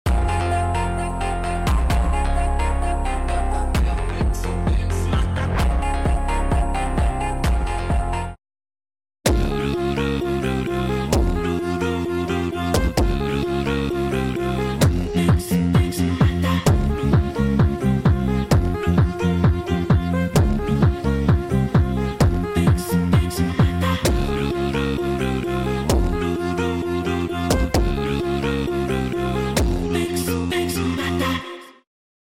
versao beatbox